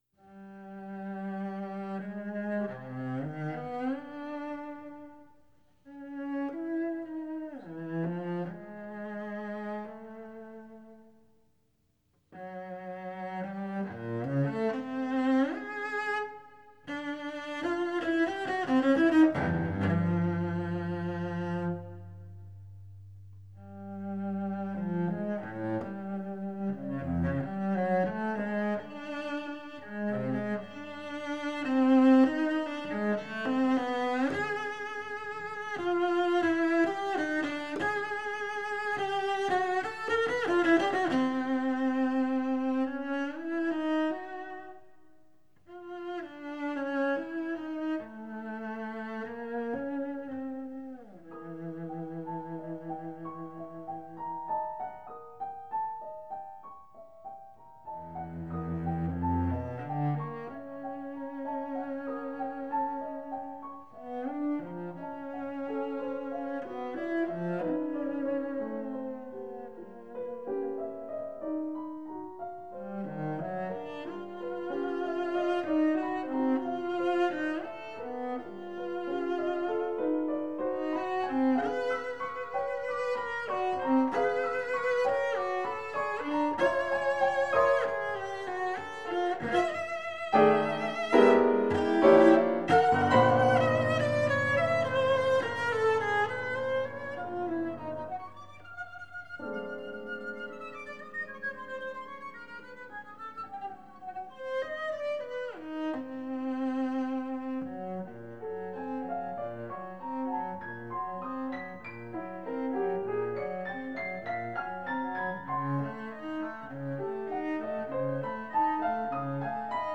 cello/piano duo